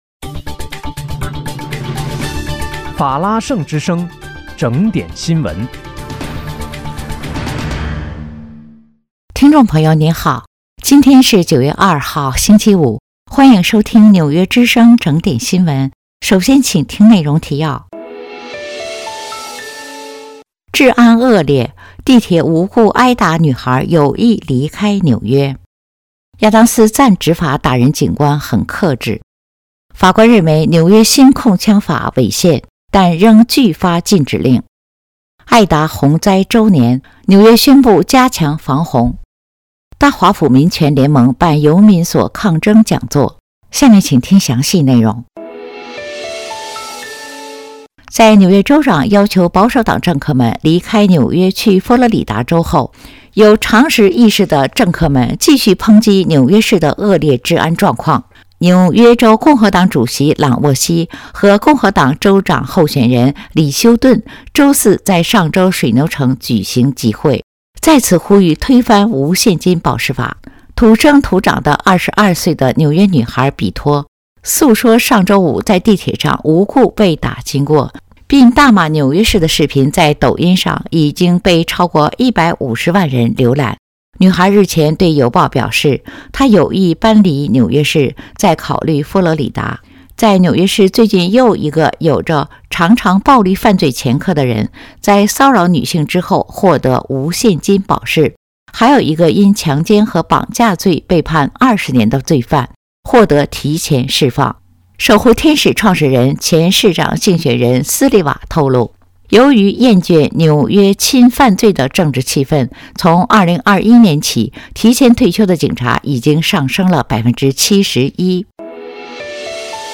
9月2日（星期五）纽约整点新闻